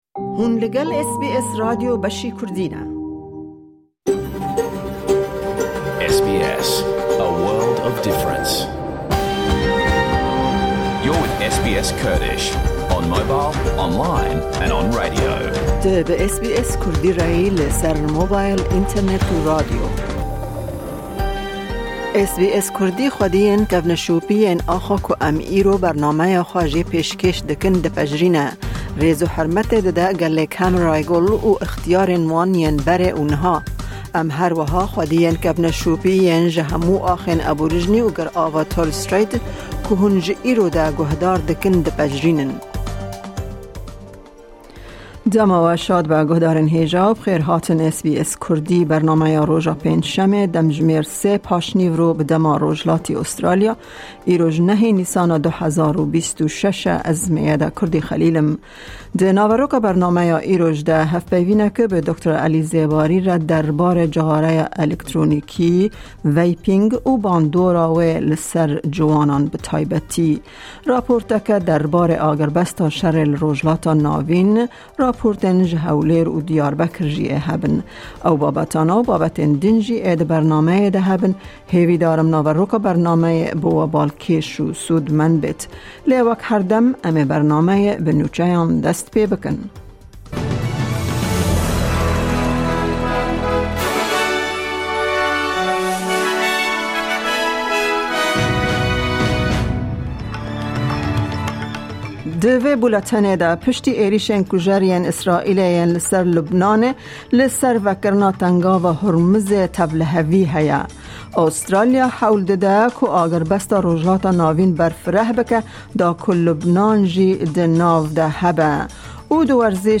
Nûçe, raportên ji Hewlêr û Amedê